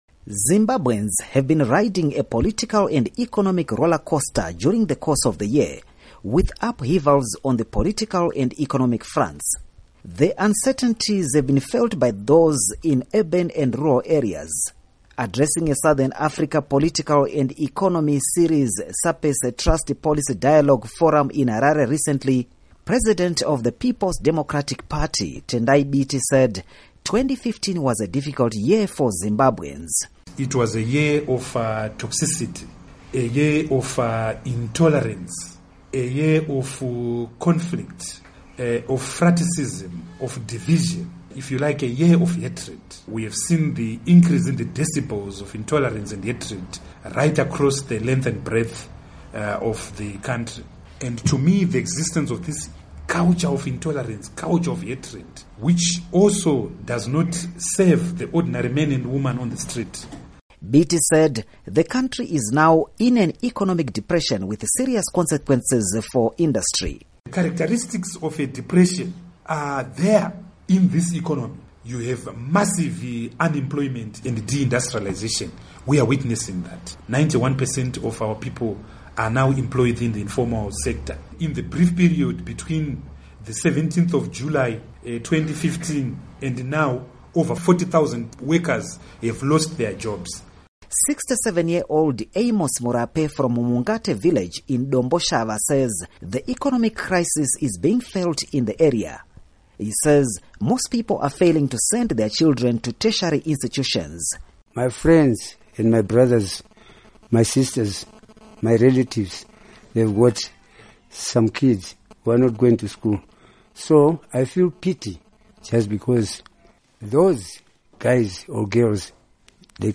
Report on Zimbabwe Economy